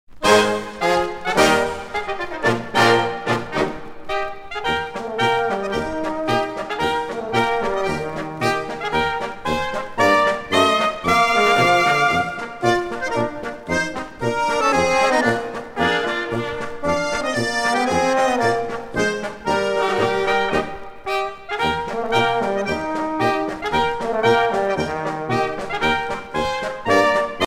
danse : marche-polka
Pièce musicale éditée